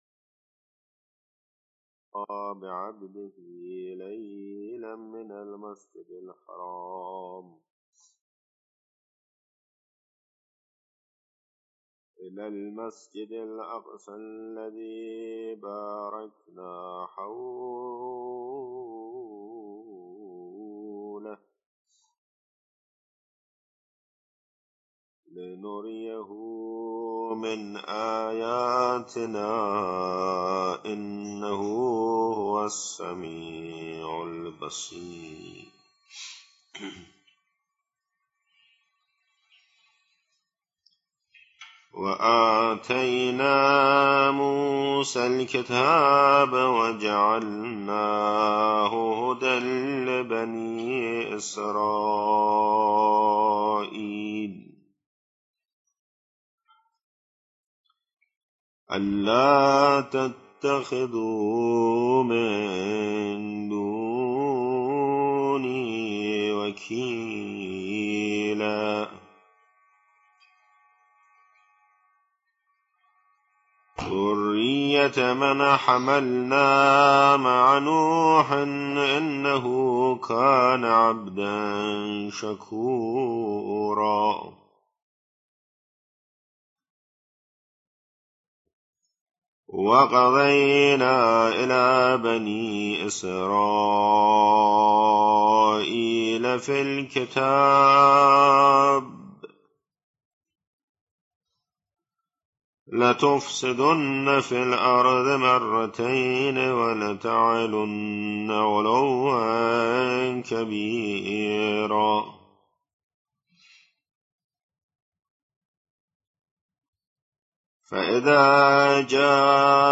تلاوة مقدار من سورة الإسراء بصوت آية الله السيد محمد محسن الطهراني
22053_Surat_alisraa_Ayatollah_Tehrani.mp3